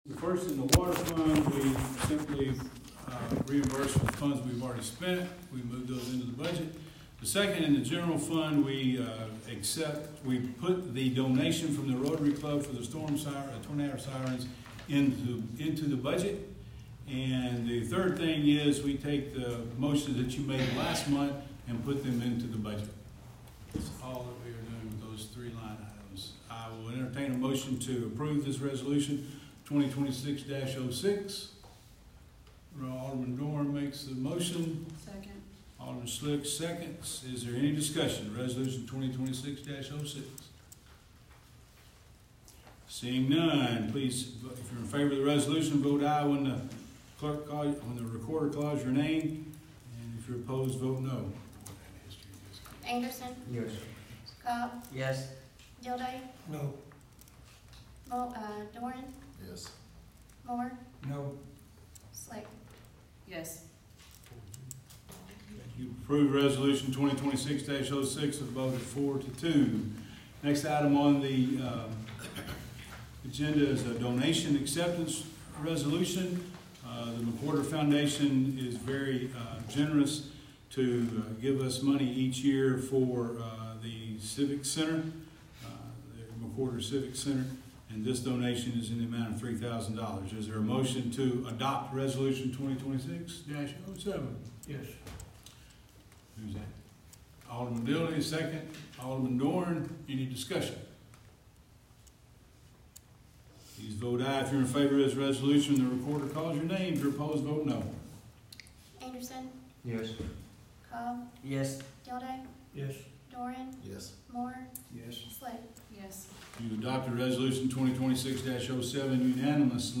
Dresden City Board Meeting